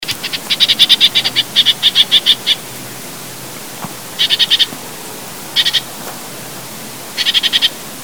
Cris d’alarme enregistrés le 03 février 2012, en Chine, province du Guangxi, à Shanli près de la ville de Yizhou.